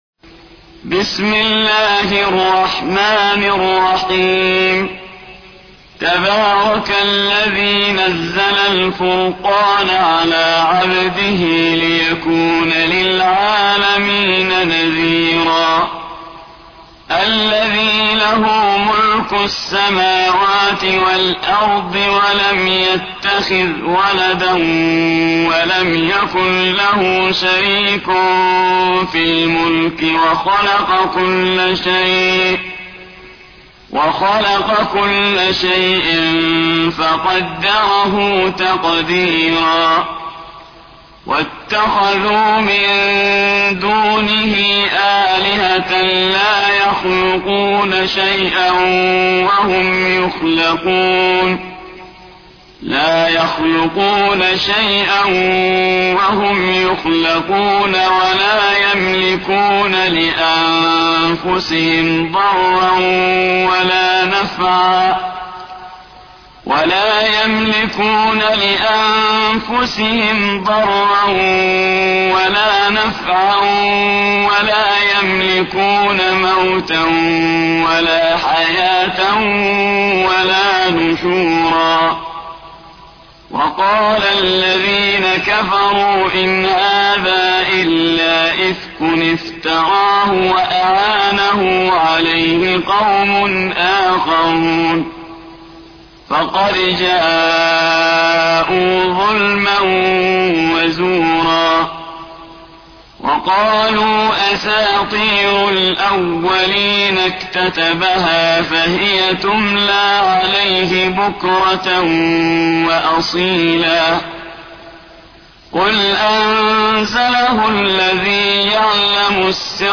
25. سورة الفرقان / القارئ